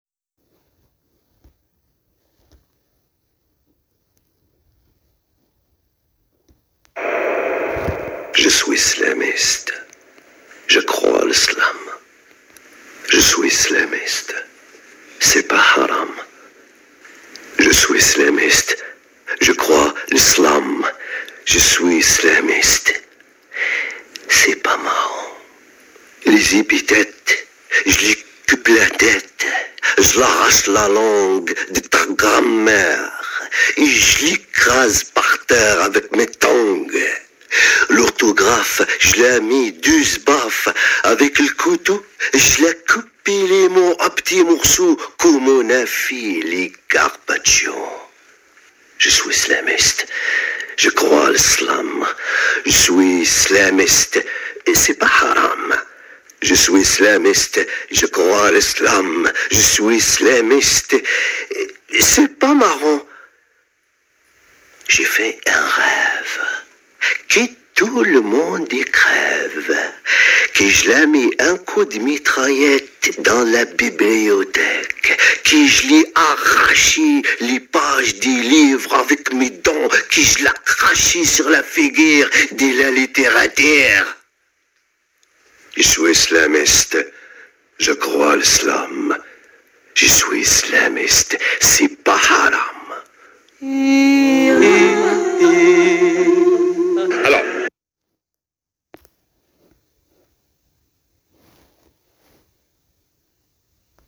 Spoken word français
Oui le fichier est pourri mais vous pouvez pas imaginer la performance d'extraire ça des internet, et avec un téléphone